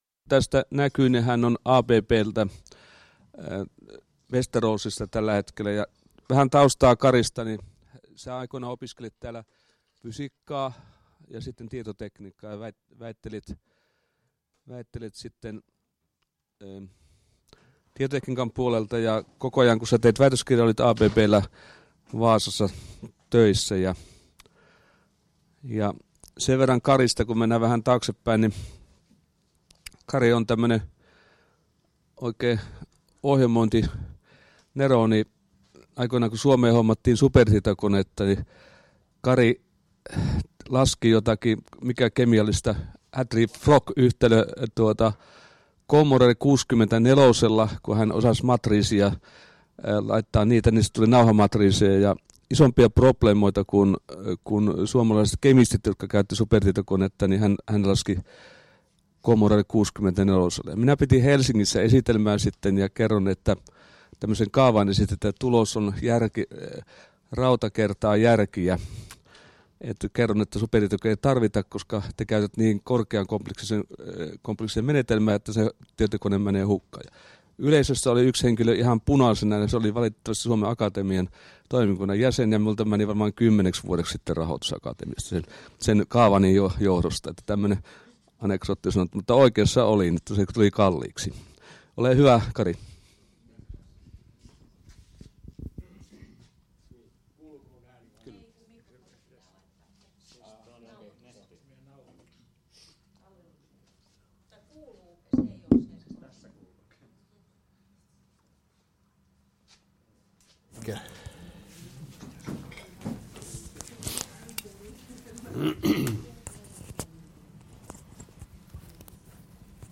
Agora ICT-foorumi 7.4.2016